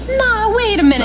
Another telling sign of perkiness is that cutesy whine she can get in her voice.